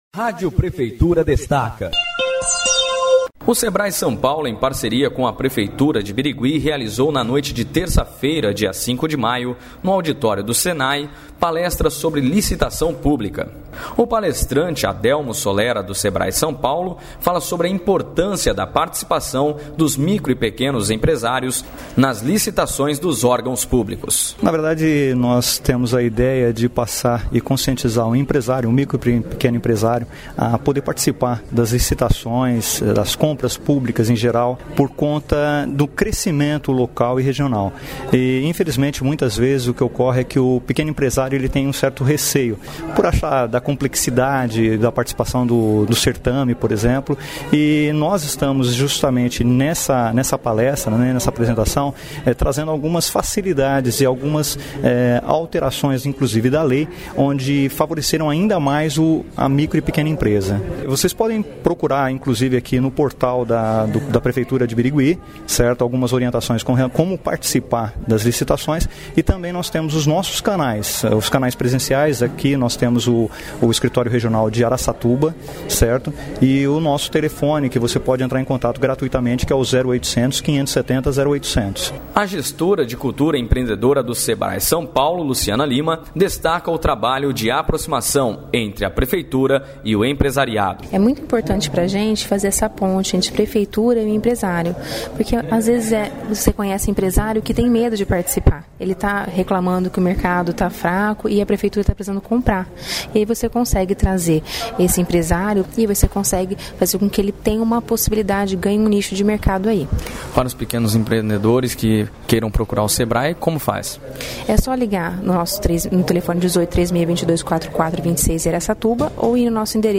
A Rádio Prefeitura este no evento